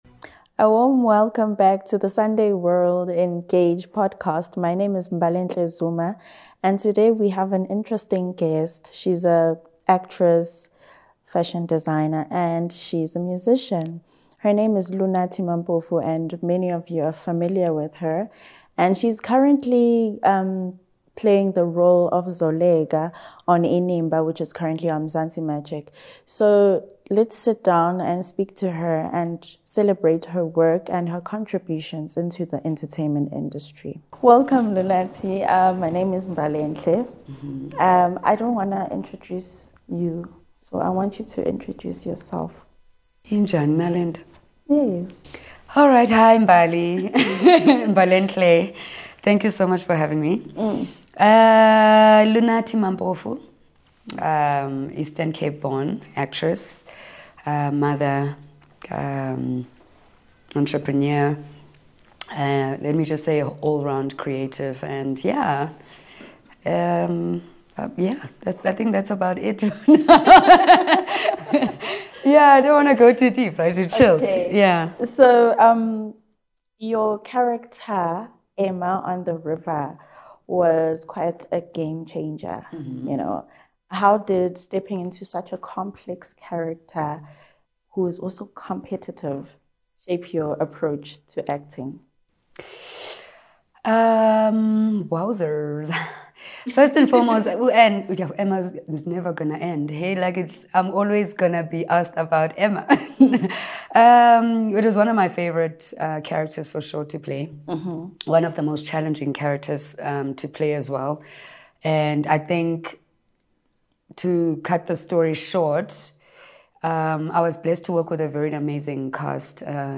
In this emotional Sunday World Engage interview